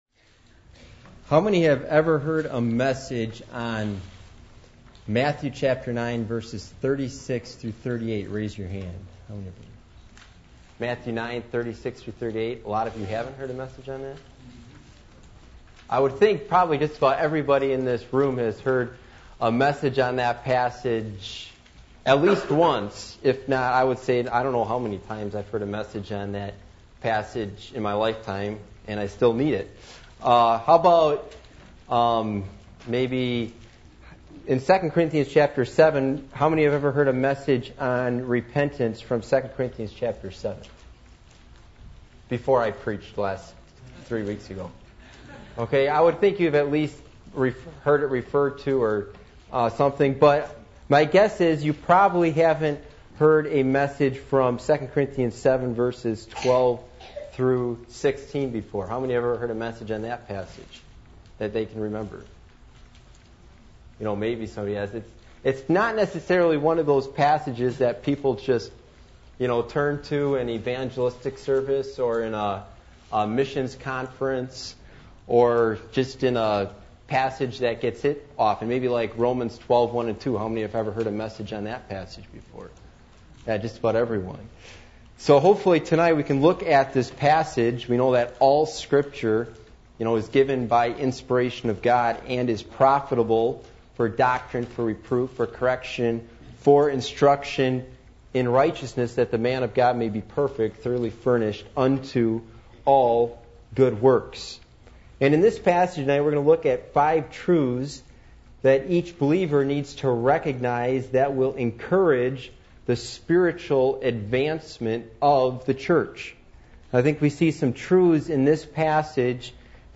Passage: 2 Corinthians 7:12-16 Service Type: Sunday Evening